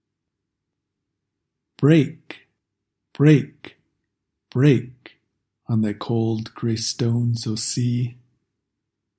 There are three stressed words in each line.